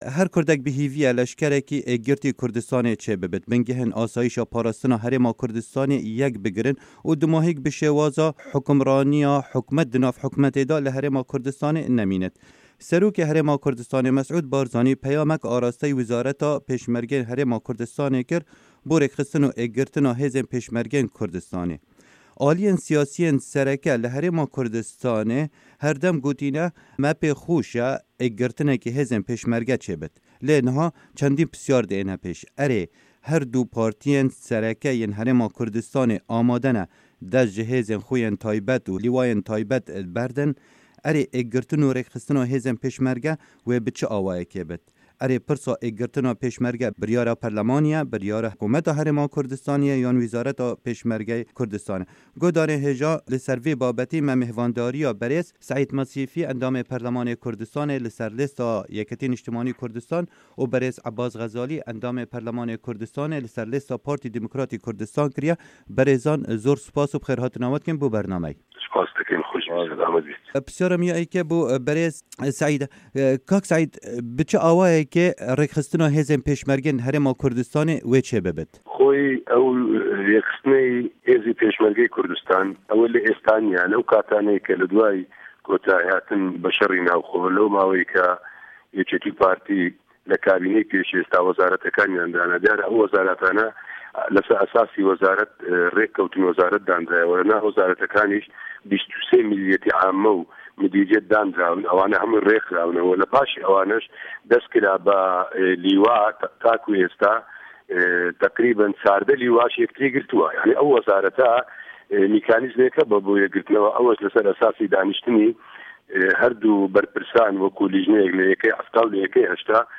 مـێزگرد: ئێکگرتنا هێزێن پێشمه‌رگه‌